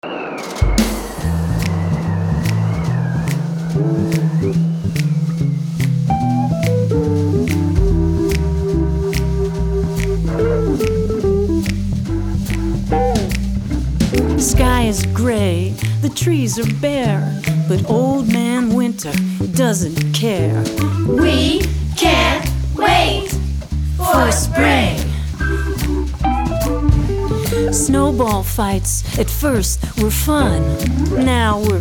Educational Songs by Subject
Vocal and
▪ The full vocal track.